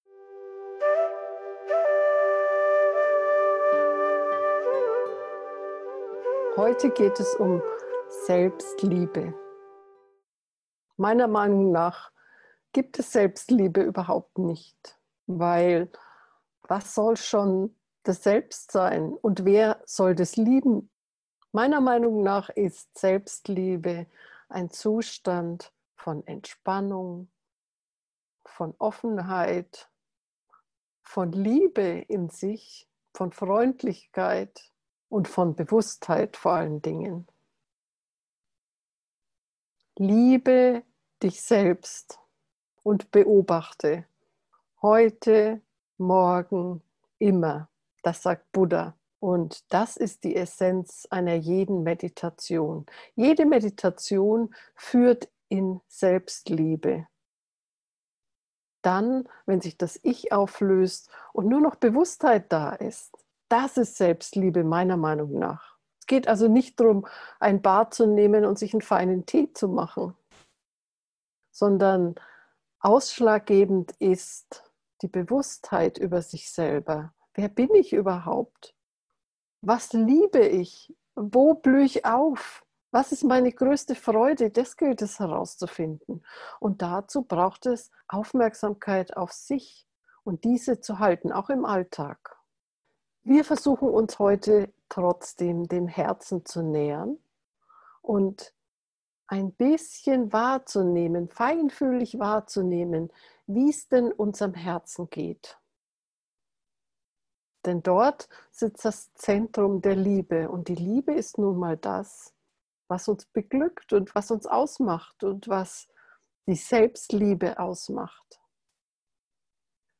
Meditationsanleitung zur geführten Meditation 'Liebe dich selbst und beobachte – heute, morgen, immer. ' Diese ...
herzmeditation-selbstliebe